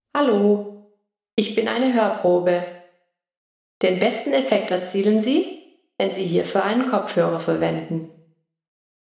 Hörprobe mit Absorber
Hoerprobe-mit-Absorber.ogg